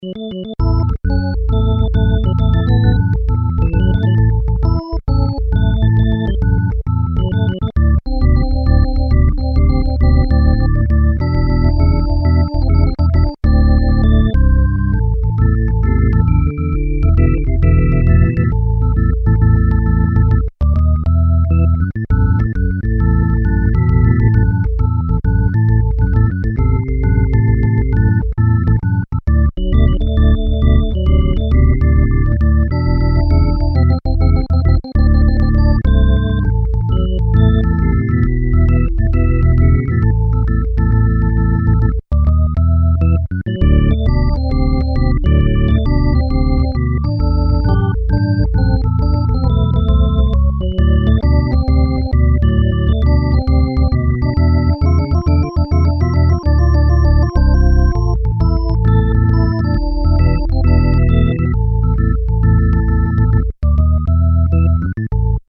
Drawbar Sound Module (1993)
Organ module expander with optional XMC-1 drawbars control.